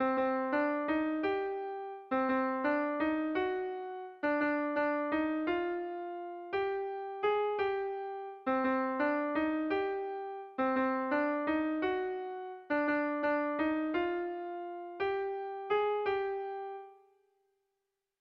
Tragikoa
Lauko handia (hg) / Bi puntuko handia (ip)
10 / 8A / 10 / 8A (hg) | 18A / 18A (ip)
A1A2A3A4